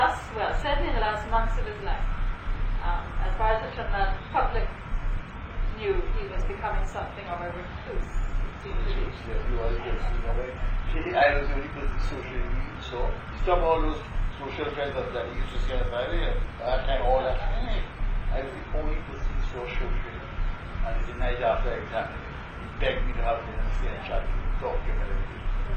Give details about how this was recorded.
2 audio cassettes